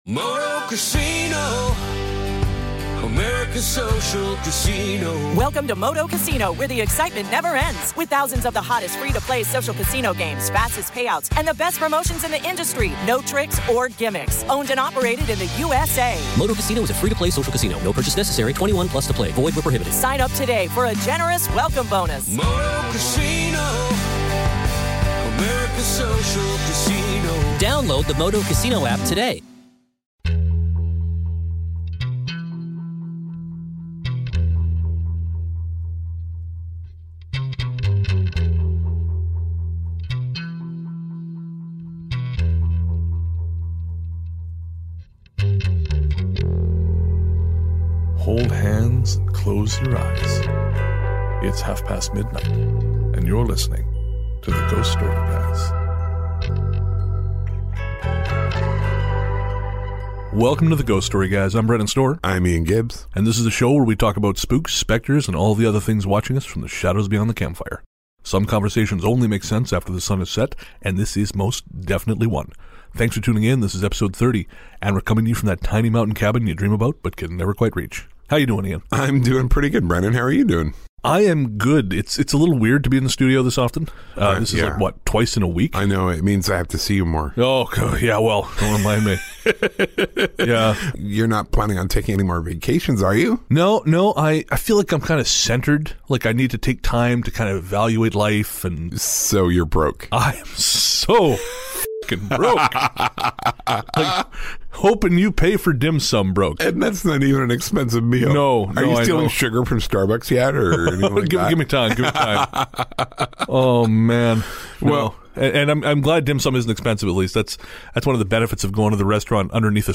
there's a whole lot of dream talk, and the guys manage to creep themselves out.